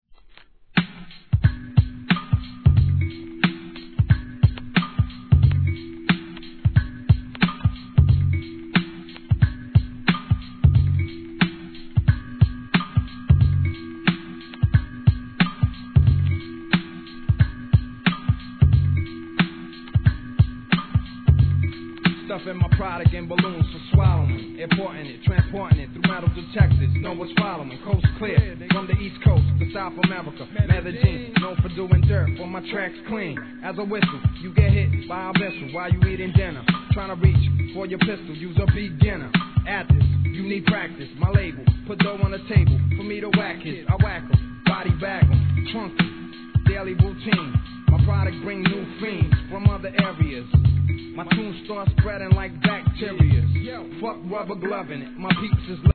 HIP HOP/R&B
CHEEPなDOPE-LOOP!!